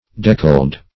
deckle-edged \dec"kle-edged`\ deckled \dec"kled\, a.